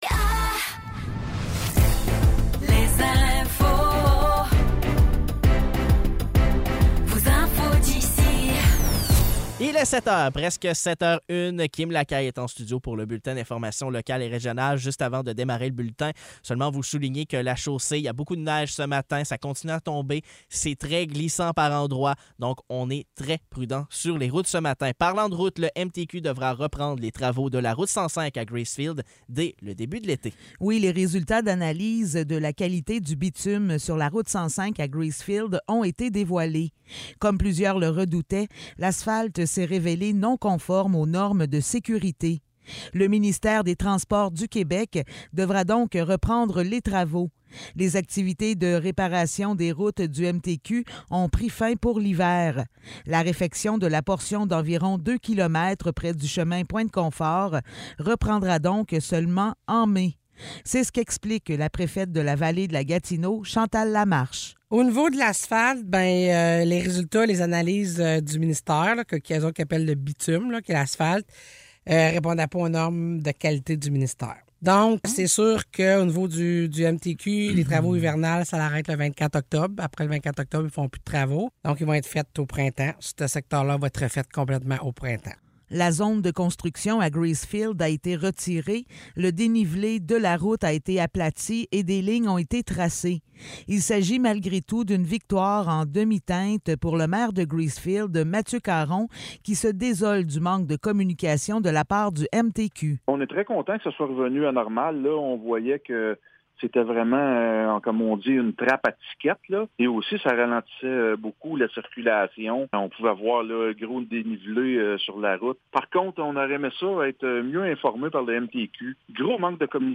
Nouvelles locales - 9 novembre 2023 - 7 h